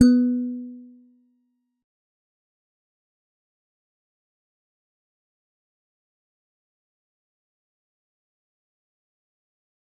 G_Musicbox-B3-mf.wav